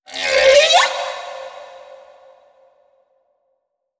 khloCritter_Female13-Verb.wav